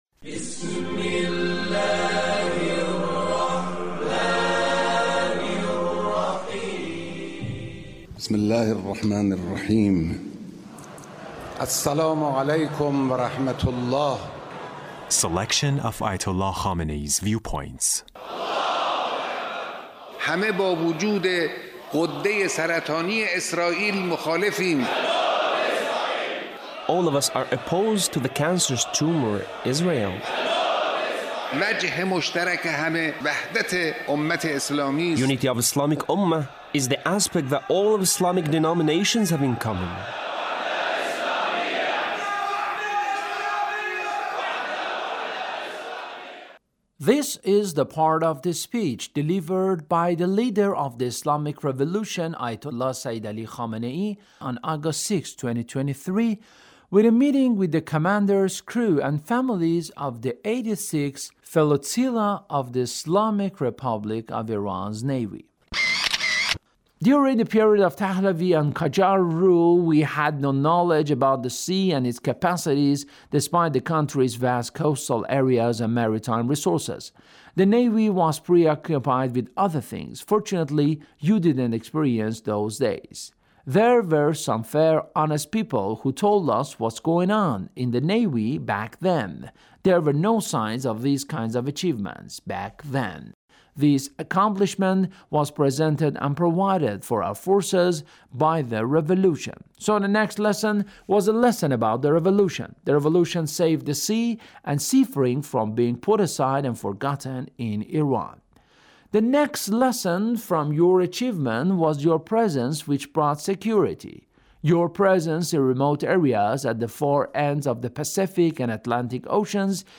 Leader's Speech in a meeting with the commanders, crew, and families of the 86th Flotilla of the Islamic Republic of Iran Navy.